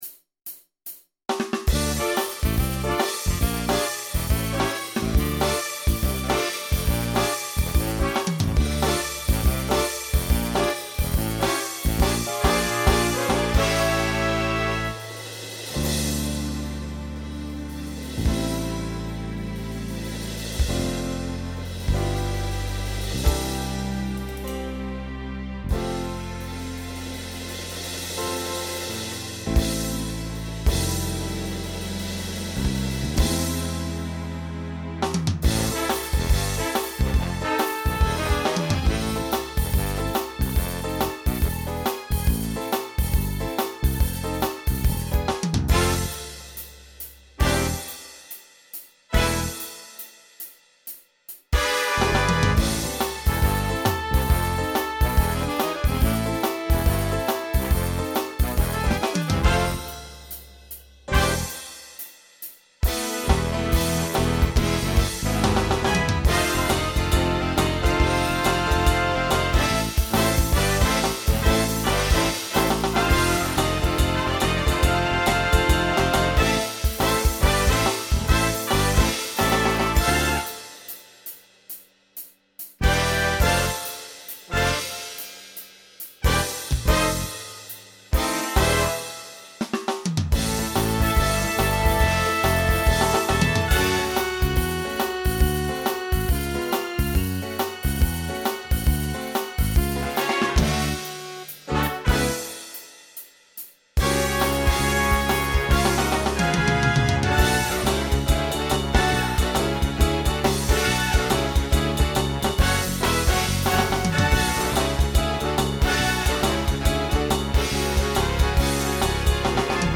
Voicing SATB Instrumental combo Genre Broadway/Film